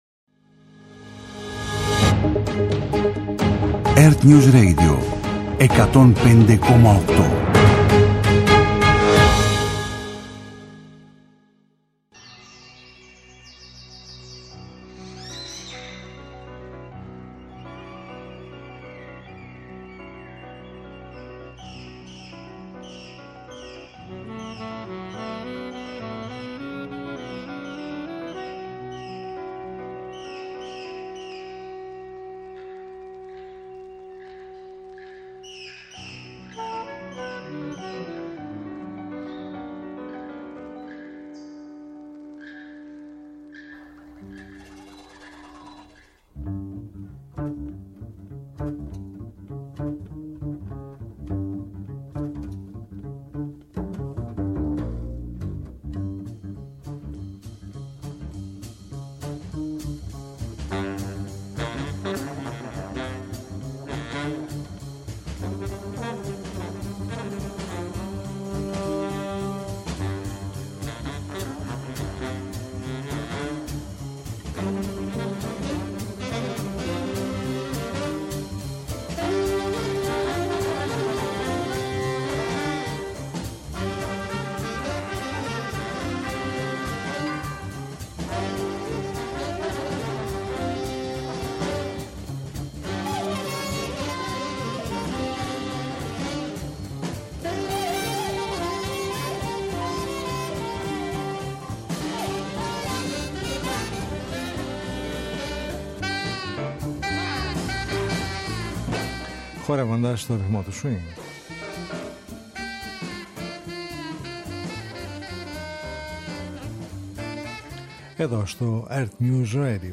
Μια εβδομαδιαία ωριαία συνάντηση με τις διάφορες μορφές της διεθνούς και της ελληνικής jazz σκηνής, κάθε Σάββατο στις 23:00 στο πρώτο Πρόγραμμα.